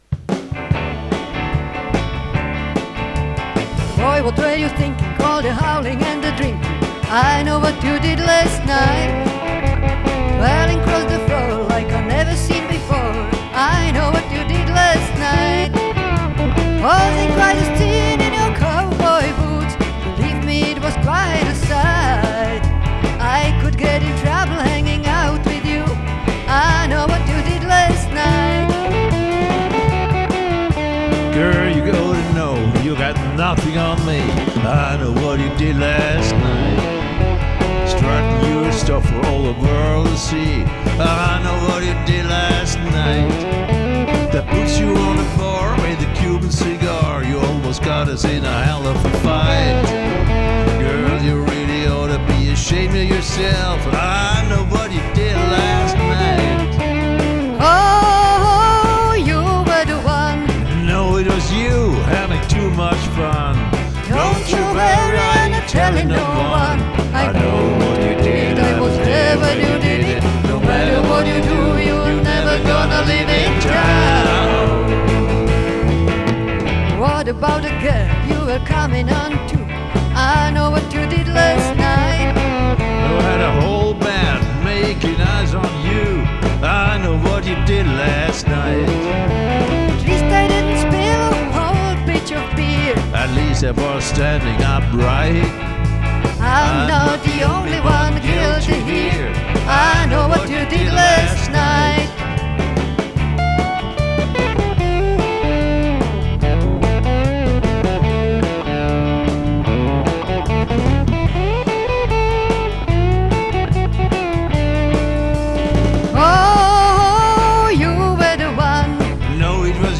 Drums
Bass
Gitarre und Gesang.